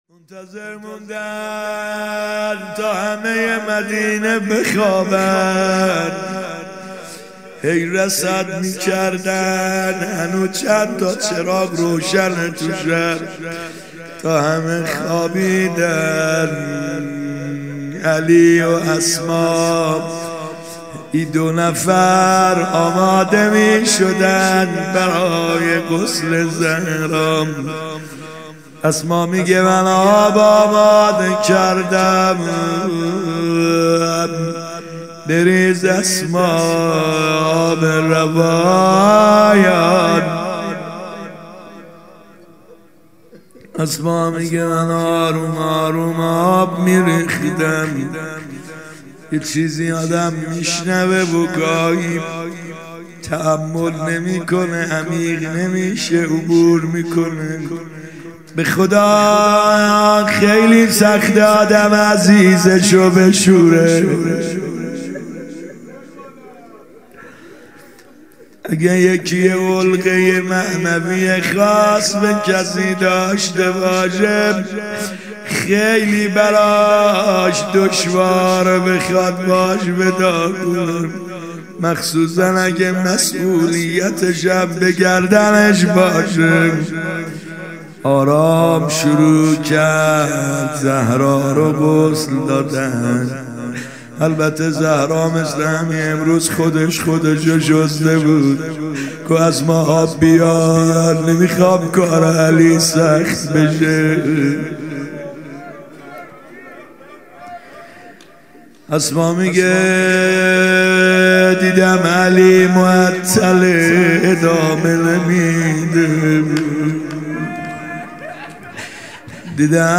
فاطمیه اول شب دوم هیئت یامهدی (عج)